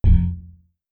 No Button.wav